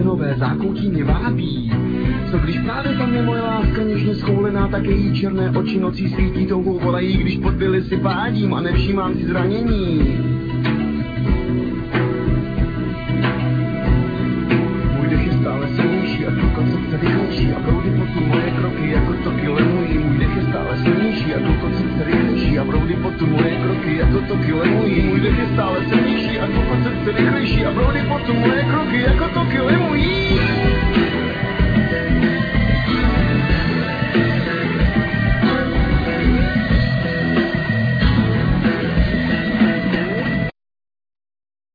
Guitar,Vocal
Bass
Drums,Percussion,Sound Wheel
Trumpet
Keyboards
Children Choir